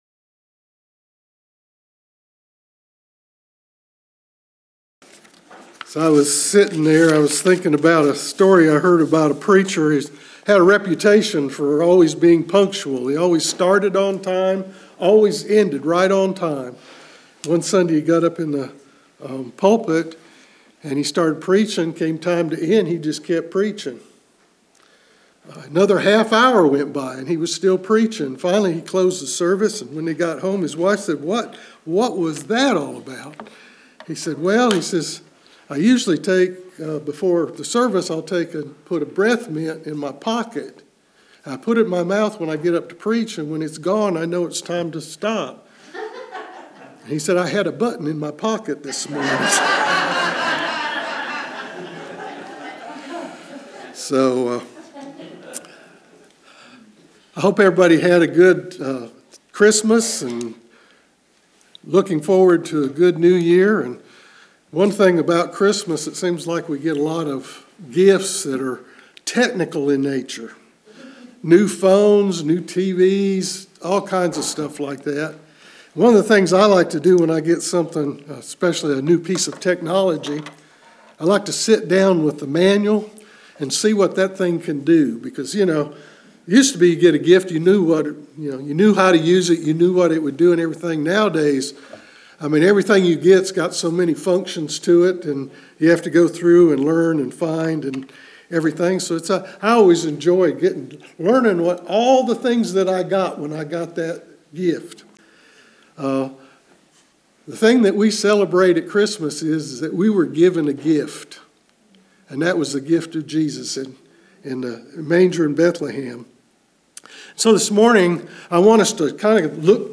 Message Type - Sermon
Occasion - Sunday Worship